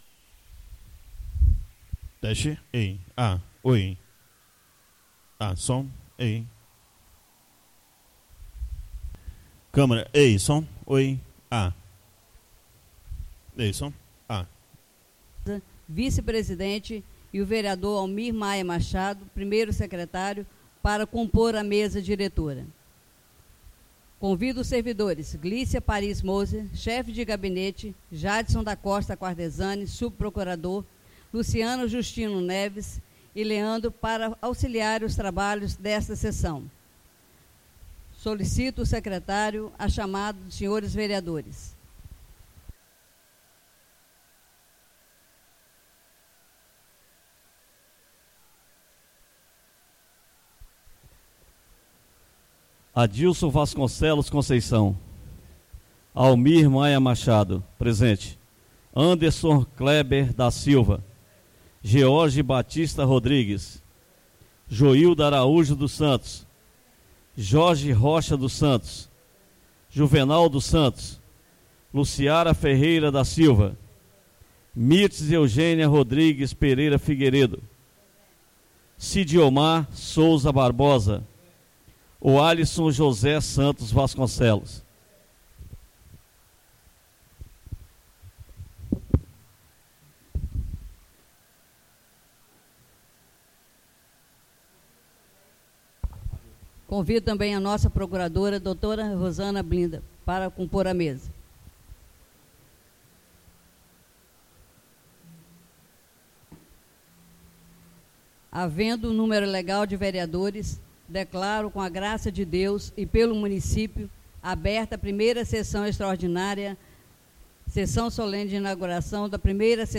1ª (PRIMEIRA) SESSÃO EXTRAORDINÁRIA PARA A DATA DE 10 DE JANEIRO DE 2017.